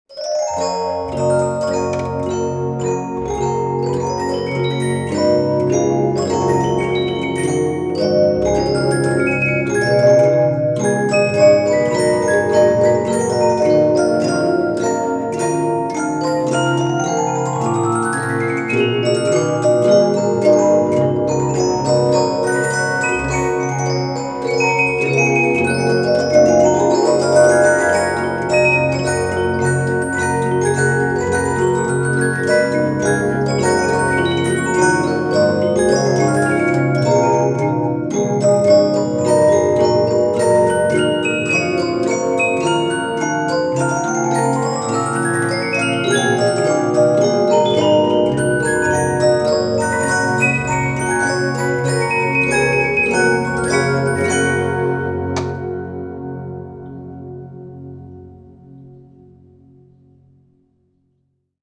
Мелодия из музыкальной шкатулки